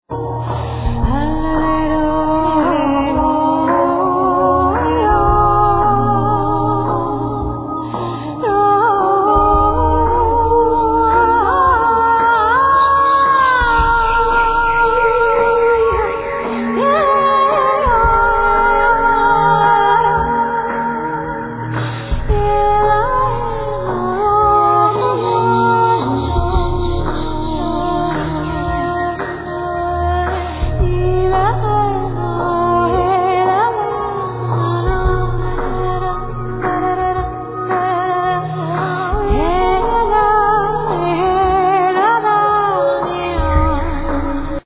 Vocals,Rainstick, Mandlin, Bells, Windchime, Ocarina, Shells
Narnian horn, Cymbals, Paper drum, Keyboards